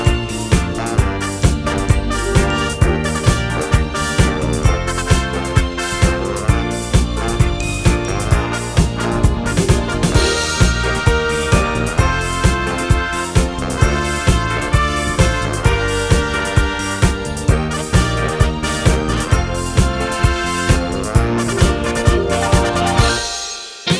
no Backing Vocals Disco 2:59 Buy £1.50